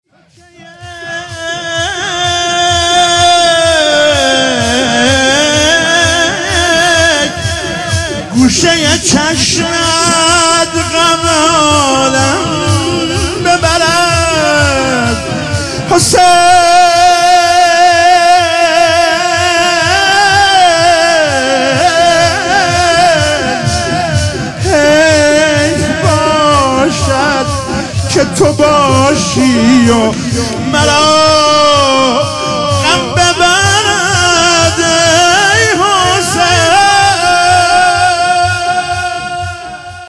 شب چهارم محرم97 - شور - تو که یک گوشه ی چشمت